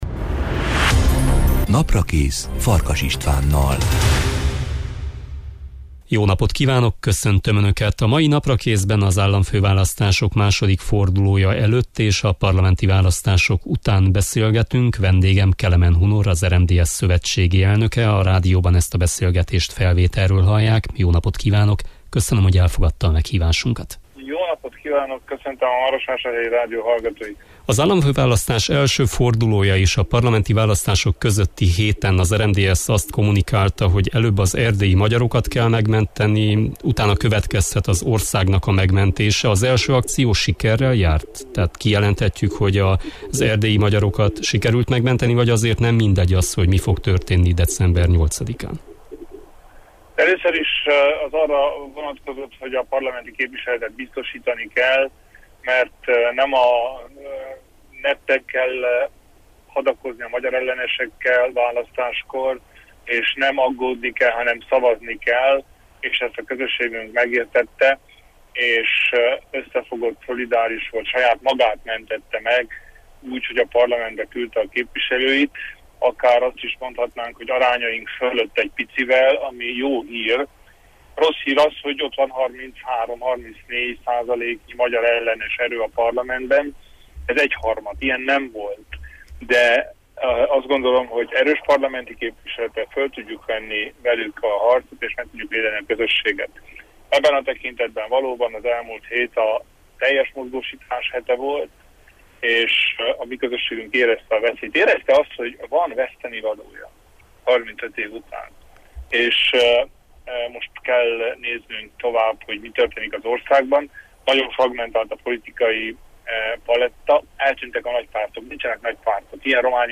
Kelemen Hunor RMDSZ-elnök a vendégem.